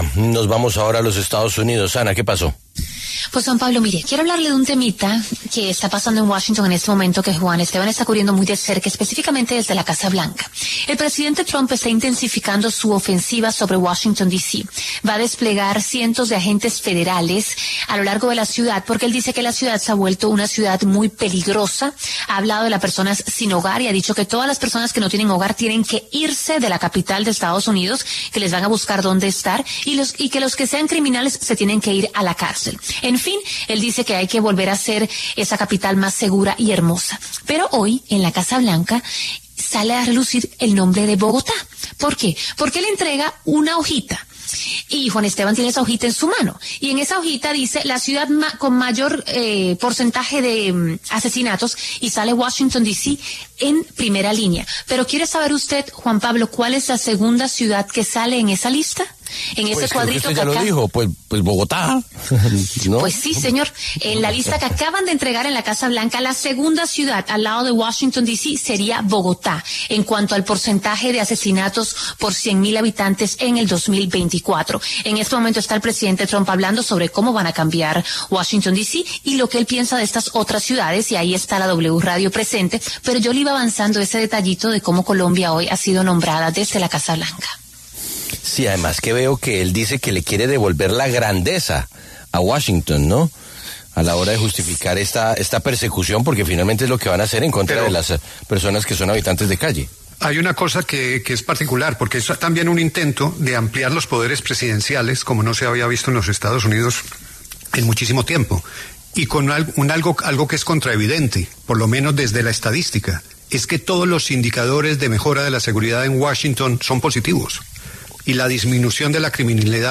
Este lunes, 11 de agosto, el presidente Donald Trump mencionó brevemente a Colombia, puntualmente a Bogotá, en una rueda de prensa en la que aseguró que Washington D.C. será “liberada”.